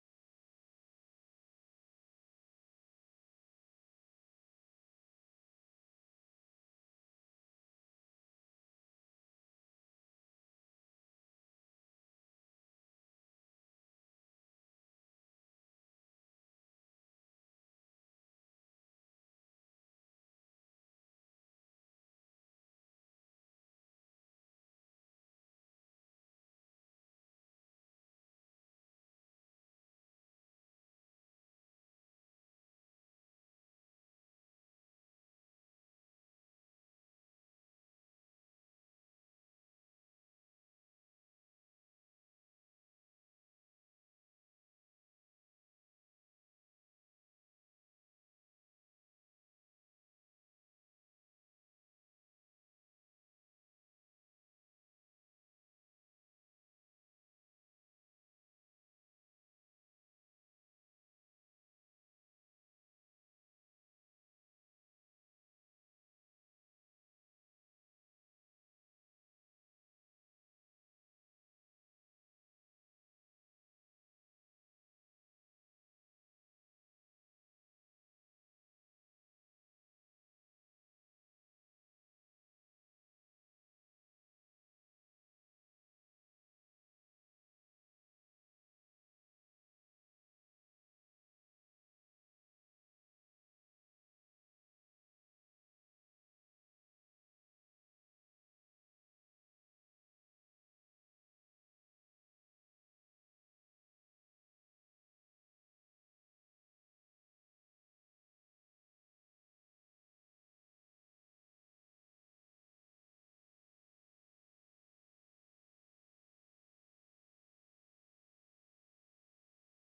0:00 Announcements (No Audio until the 3 minute mark)